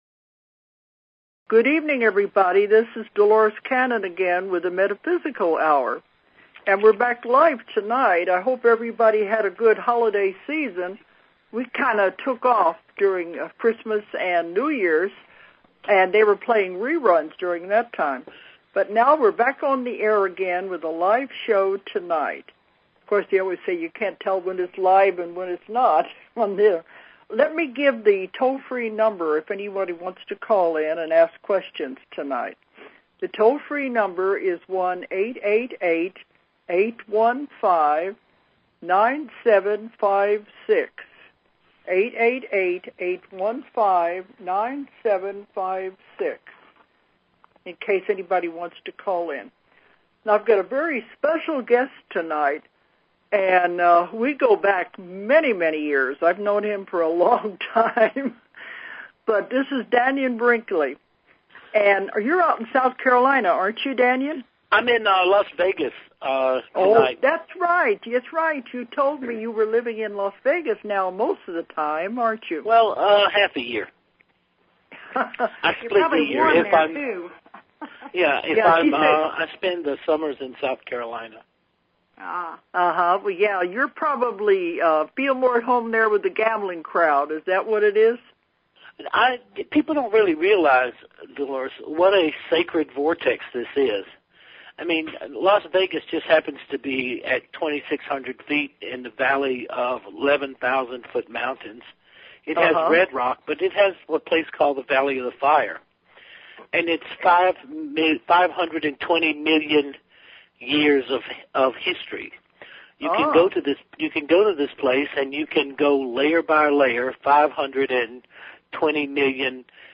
Guest, DANNION BRINKLEY